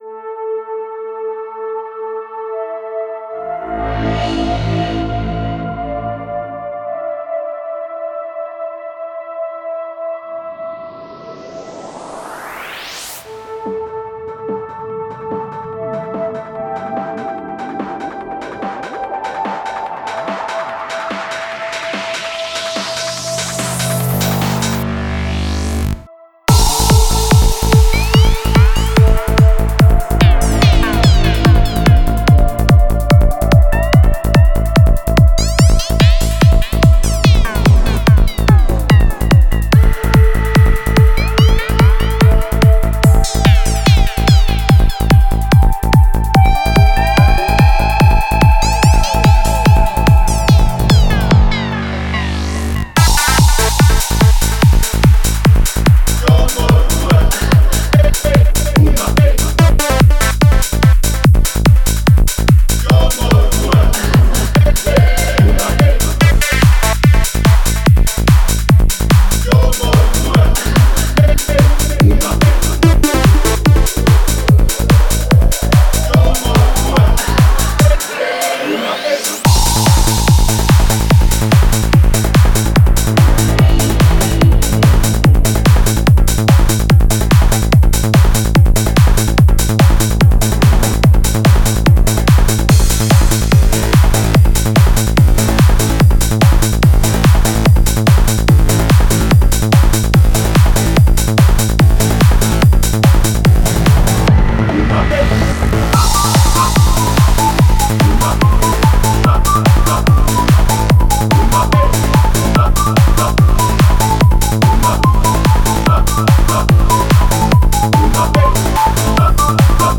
Style: Full-On
Quality: 320 kbps / Stereo